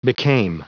Prononciation du mot became en anglais (fichier audio)
Prononciation du mot : became